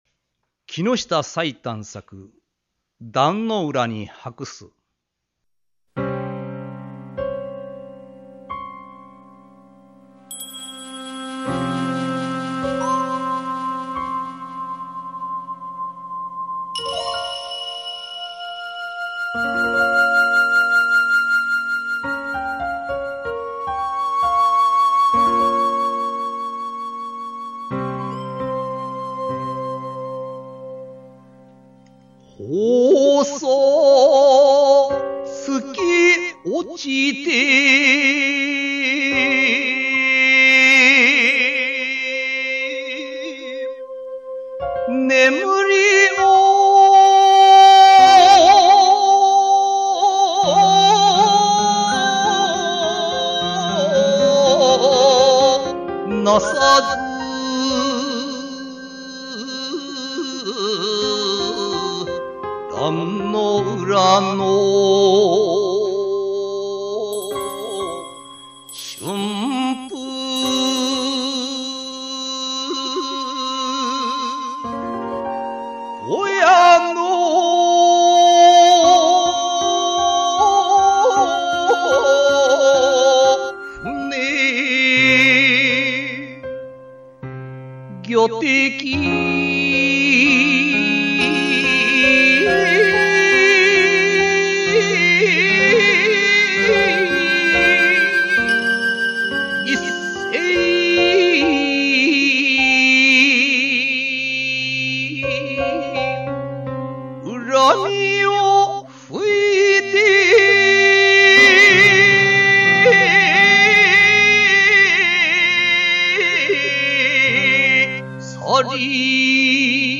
漢詩紹介